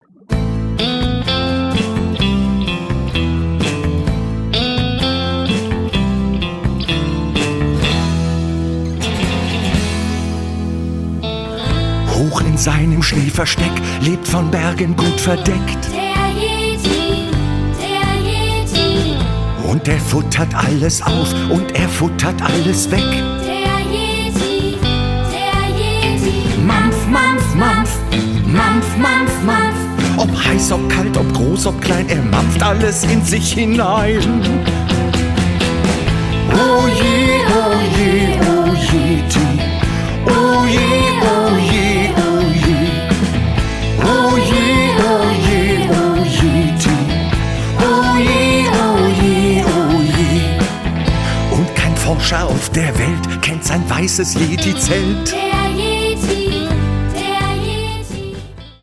Ein winterliches Musical- und Mitmachbuch mit CD